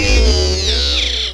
zoomDOWN.ogg